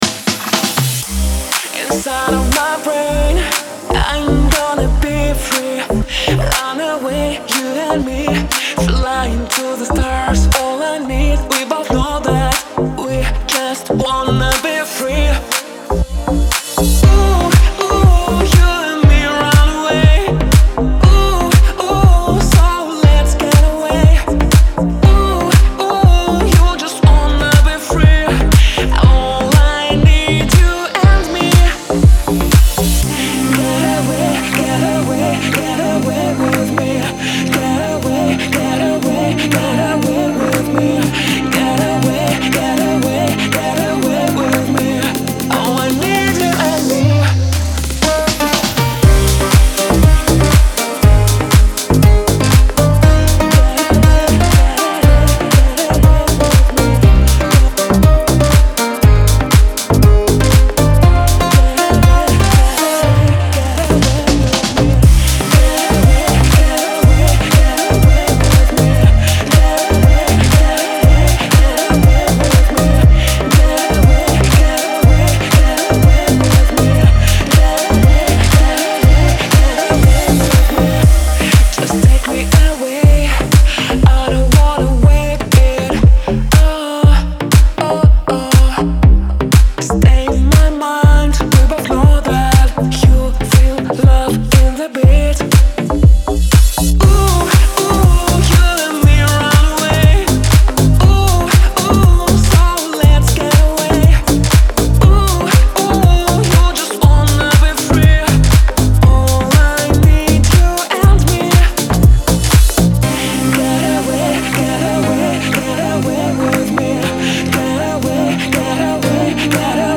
это яркий трек в жанре EDM